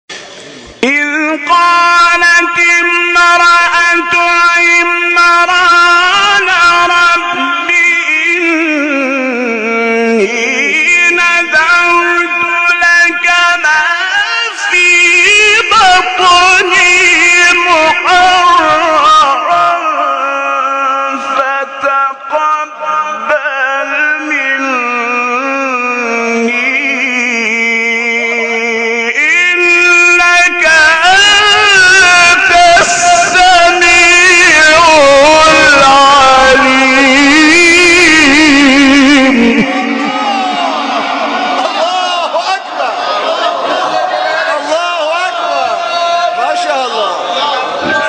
گروه شبکه اجتماعی: مقاطعی از تلاوت قاریان ممتاز و بین‌المللی کشور که به‌تازگی در شبکه اجتماعی تلگرام منتشر شده است، می‌شنوید.